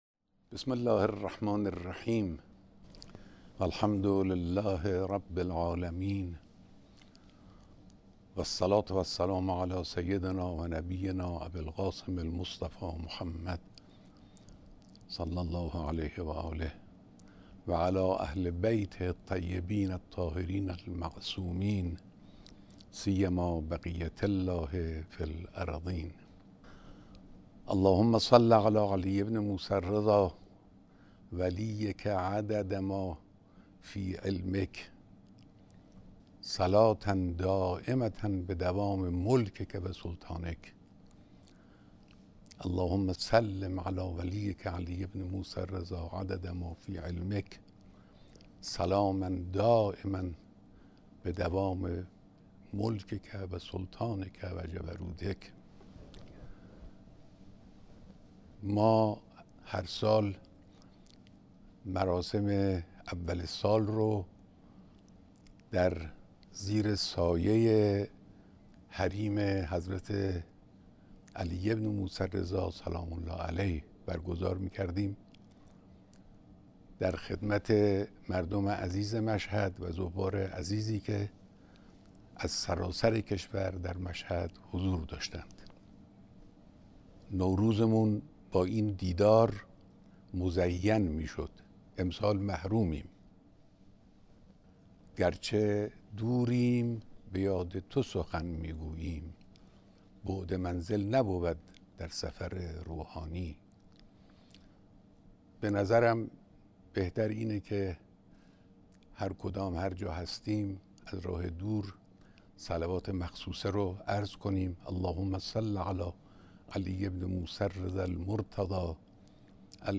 سخنان رهبر معظم انقلاب اسلامی با ملت شریف ایران
بیانات خطاب به ملت ایران به‌ مناسبت عید مبعث